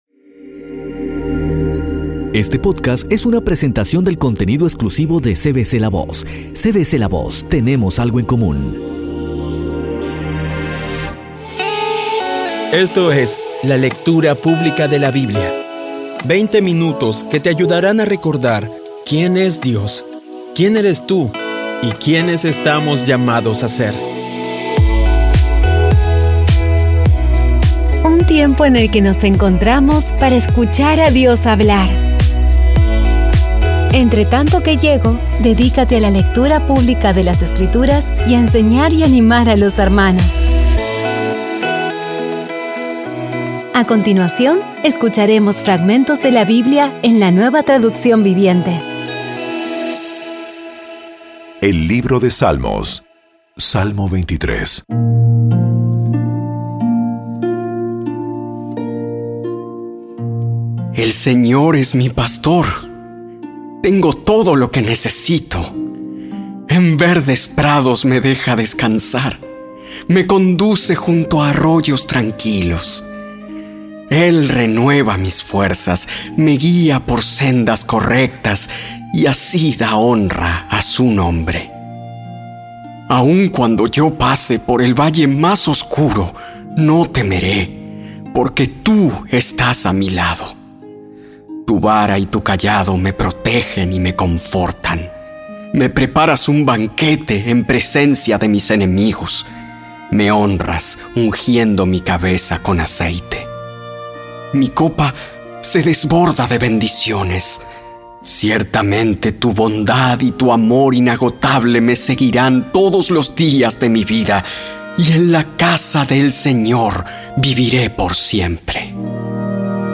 Con tan solo veinte minutos diarios, vas cumpliendo con tu propósito de estudiar la Biblia completa en un año. Poco a poco y con las maravillosas voces actuadas de los protagonistas vas degustando las palabras de esa guía que Dios nos dio.